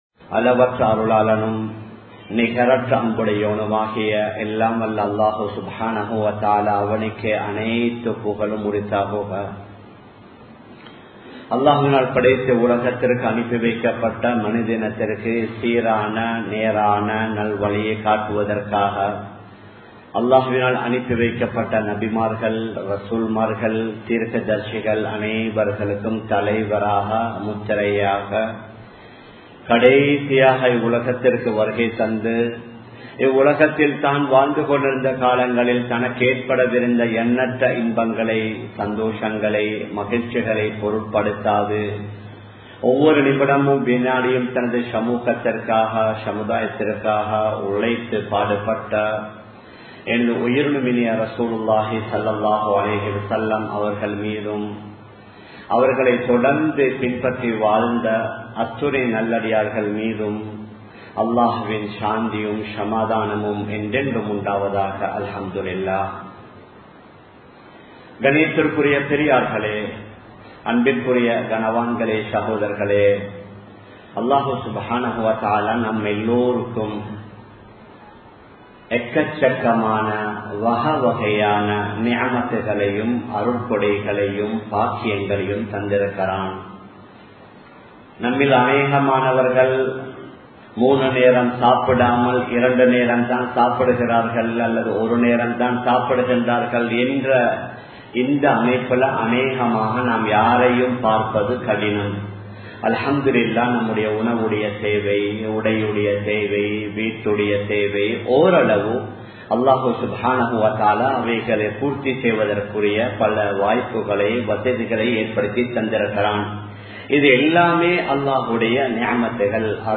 Islamiya Paarvaiel Pettroarhal (இஸ்லாமிய பார்வையில் பெற்றோர்கள்) | Audio Bayans | All Ceylon Muslim Youth Community | Addalaichenai
Colombo 11, Samman Kottu Jumua Masjith (Red Masjith)